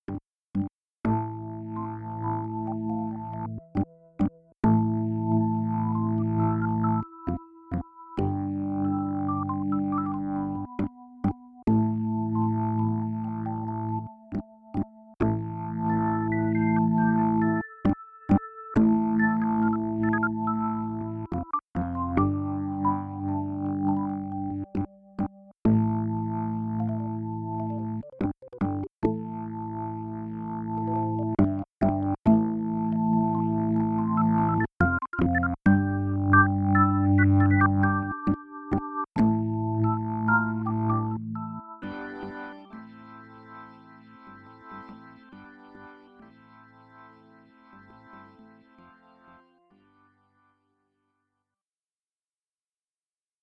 Klangbeispiele: amt-db-33-1 amt-db-33-2 amt-db-33-3 Fazit: Die Software ist einfach zu bedienen und bietet einen tollen Sound.